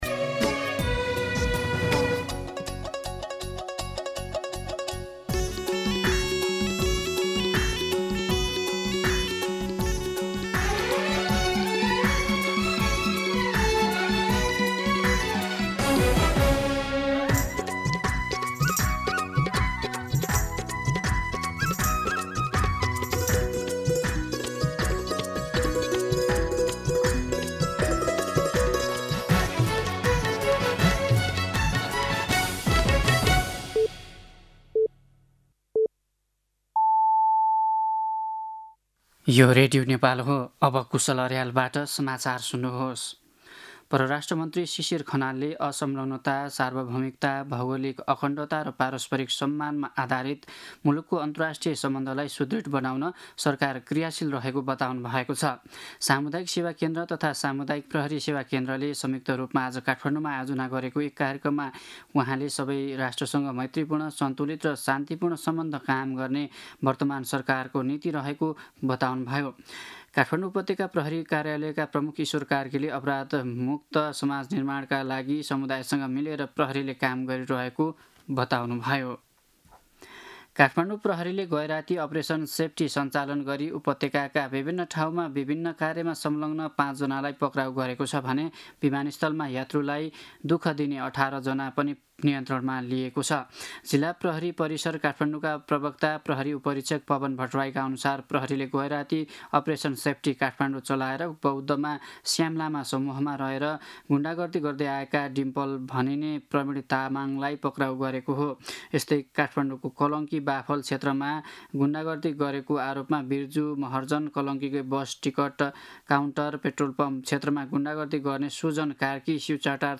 दिउँसो १ बजेको नेपाली समाचार : २१ चैत , २०८२
1pm-News-12-21.mp3